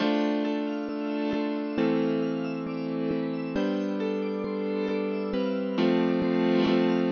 悲伤的钢琴旋律第二部分
描述：用FL键和钟声制作的Ano旋律，带着毛茸茸的节拍
Tag: 135 bpm Hip Hop Loops Piano Loops 1.20 MB wav Key : Unknown FL Studio